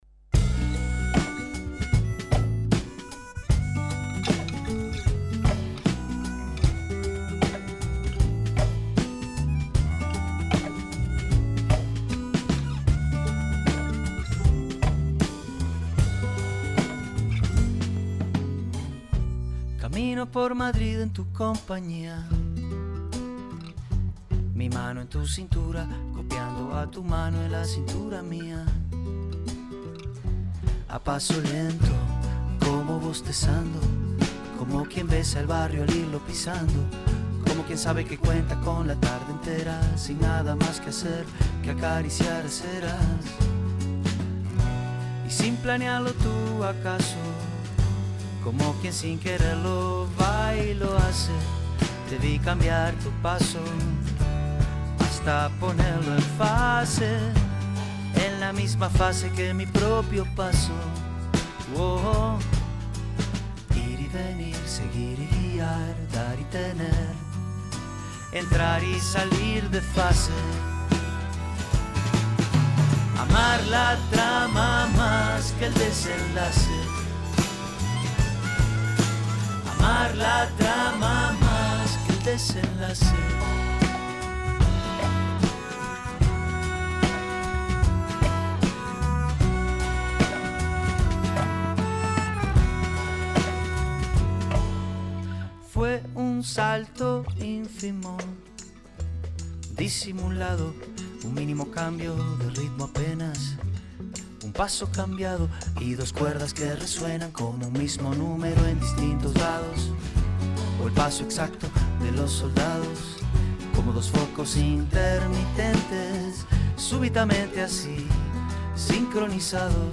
El prestigioso pianista y DJ, tanto a nivel nacional como internacional, Luciano Supervielle contó en Un Mundo Cualquiera sobre este show pero también sobre su trayectoria.